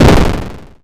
Explode_01.mp3